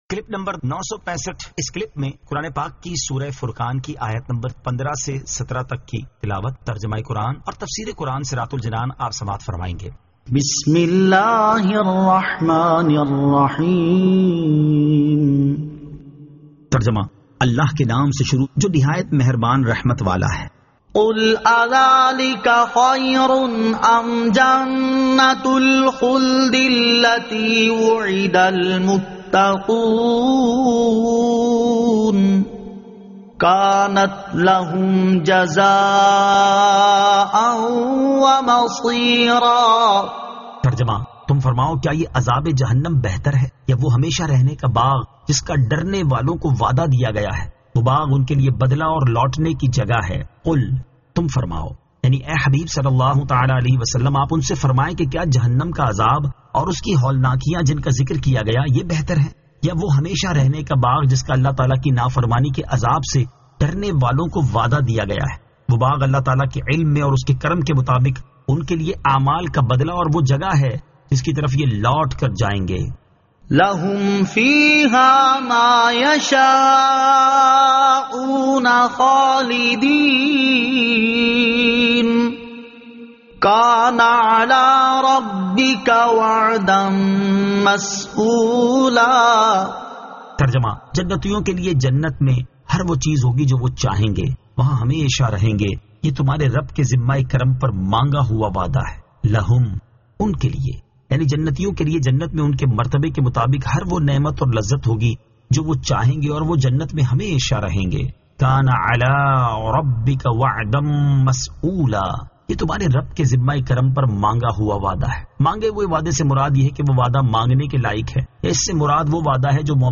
Surah Al-Furqan 15 To 17 Tilawat , Tarjama , Tafseer